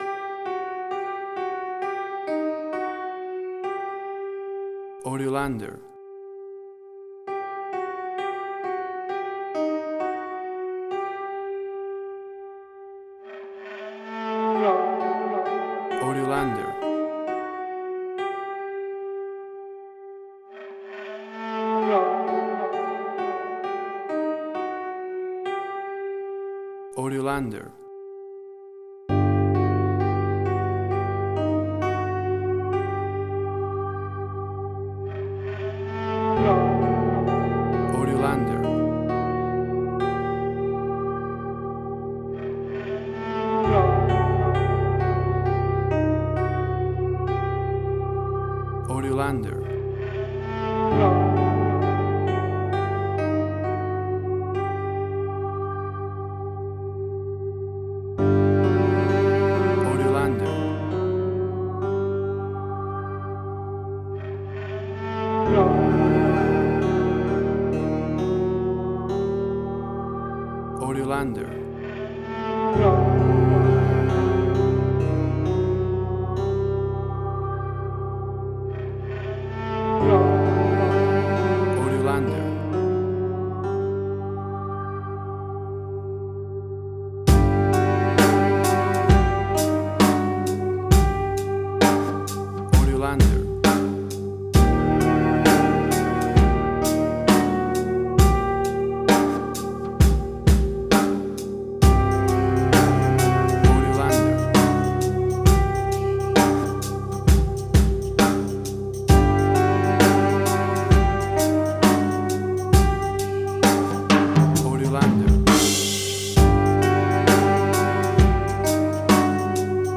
Suspense, Drama, Quirky, Emotional.
Tempo (BPM): 66